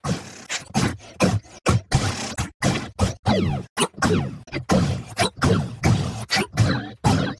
(beep-boxing).